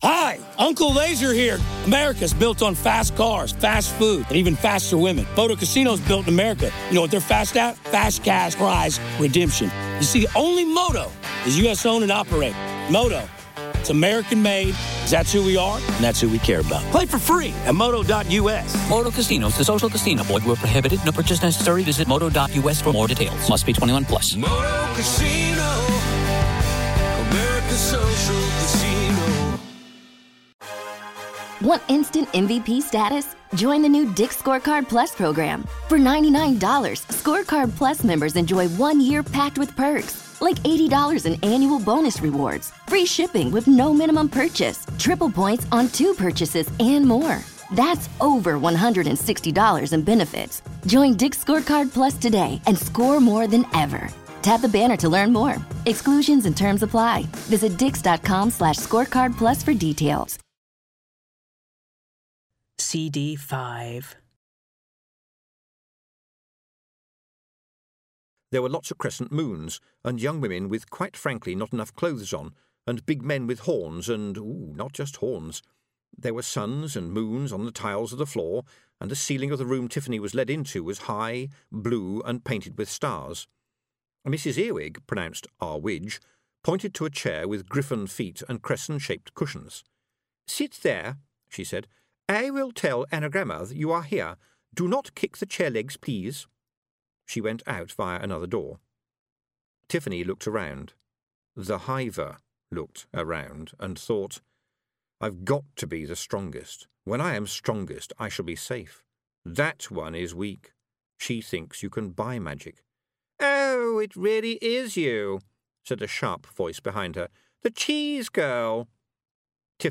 Discworld 32 - A Hat Full of Sky by Terry Pratchett (Full Audiobook) Podcast - Discworld 32 - A Hat Full of Sky by Terry Pratchett - 05 of 08 Episodes | Free Listening on Podbean App